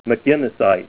Say MCGUINNESSITE